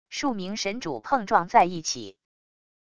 数名神主碰撞在一起wav音频